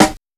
• Old School Smooth Steel Snare Drum Sound D# Key 109.wav
Royality free snare single hit tuned to the D# note. Loudest frequency: 1650Hz
old-school-smooth-steel-snare-drum-sound-d-sharp-key-109-5aO.wav